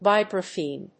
音節vi・bra・phone 発音記号・読み方
/vάɪbrəfòʊn(米国英語), vάɪbrəf`əʊn(英国英語)/